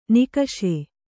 ♪ nikaṣe